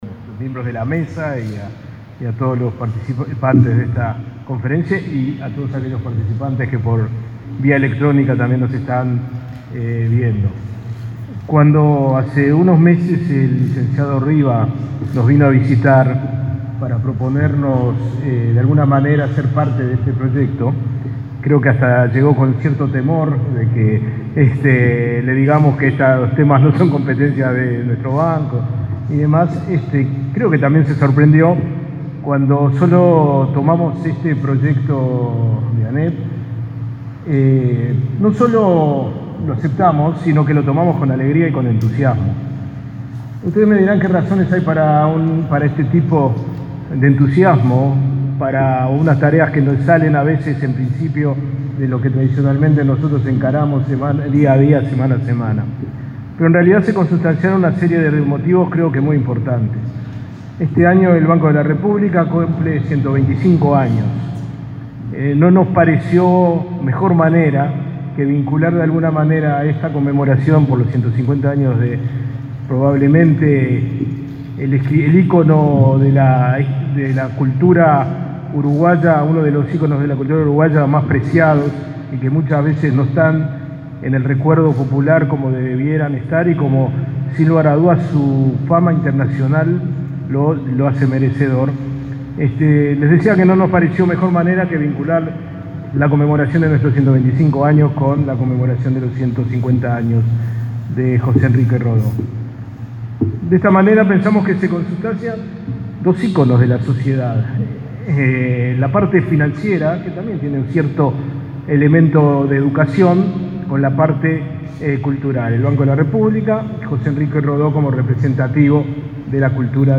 Palabras del presidente del Codicen, Robert Silva, y del director del BROU, Max Sapolinski
Este martes 3, Silva y Sapolinski participaron de la presentación del concurso de cuentos Dibujando a Rodó.